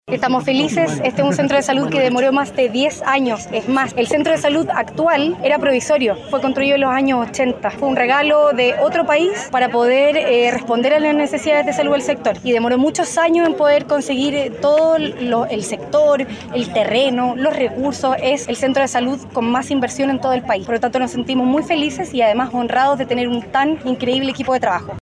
La alcaldesa de Viña del Mar, Macarena Ripamonti, apuntó que este centro de salud es el que cuenta con una mayor inversión en todo el país.